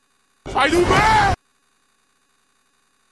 Áudio Sai do meio, menino, Oxe! Som de Carros Acelerando
Categoria: Sons virais
Engraçado, curto e direto, esse áudio viral conquistou a internet e continua sendo uma das escolhas favoritas de quem adora fazer zoeiras.